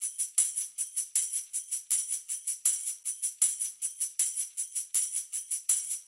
Index of /musicradar/sampled-funk-soul-samples/79bpm/Beats
SSF_TambProc1_79-03.wav